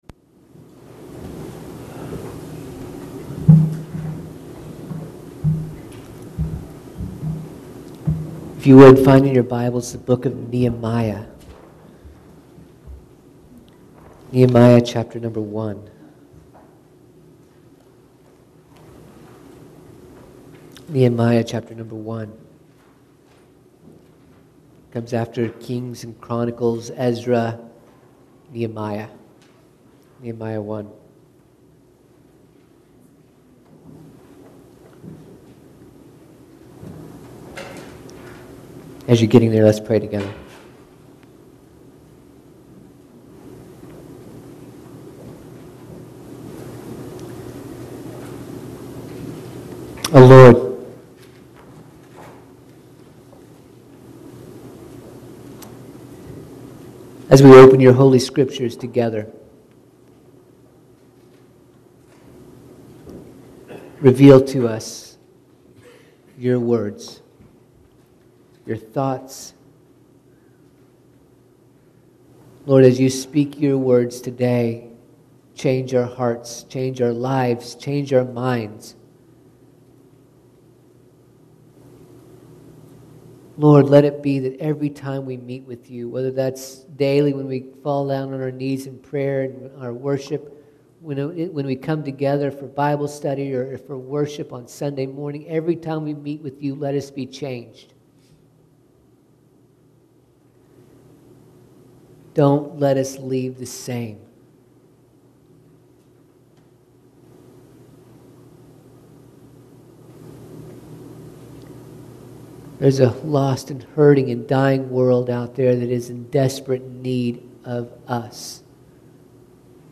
The Lord directed me to preach on these reformation theses in 2018.